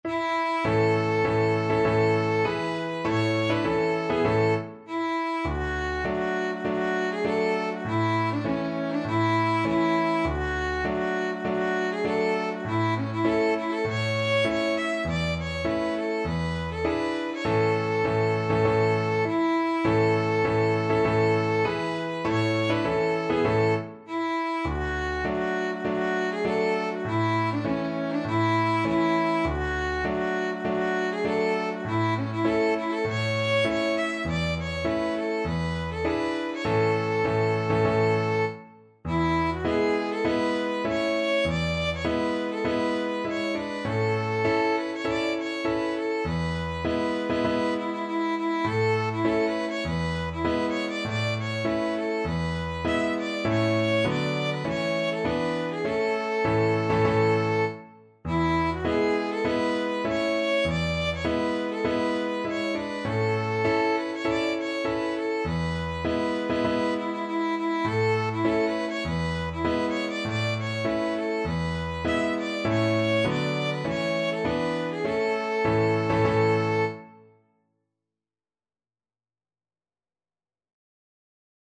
Violin
Traditional Music of unknown author.
A major (Sounding Pitch) (View more A major Music for Violin )
4/4 (View more 4/4 Music)
Classical (View more Classical Violin Music)